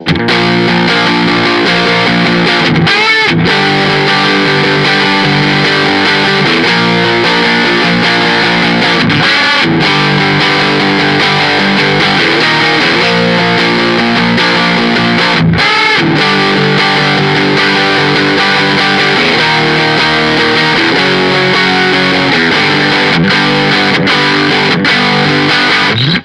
• Low Gain Sensitivity, crunch sound
Crunch
RAW AUDIO CLIPS ONLY, NO POST-PROCESSING EFFECTS